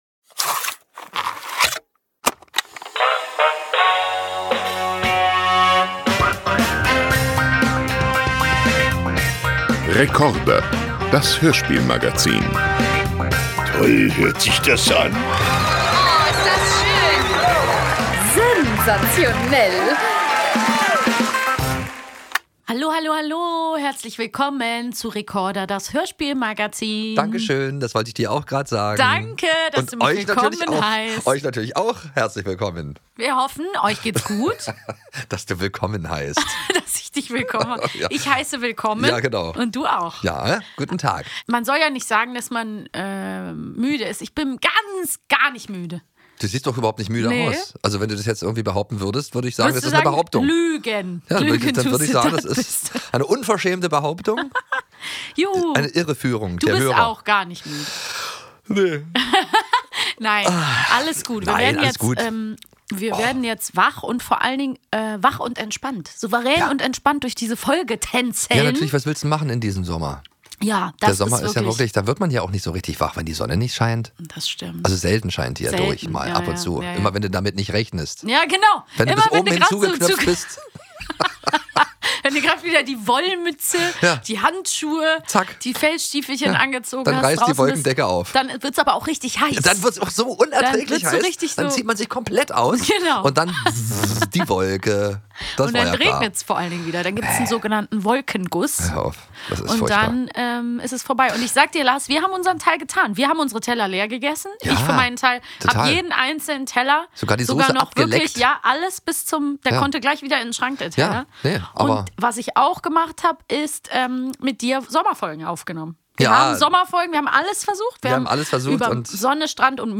Heute gibt’s was Besonderes auf die Ohren: ein musikalisches Hörspiel, das (fast) jede*r kennt – “Peter und der Wolf”.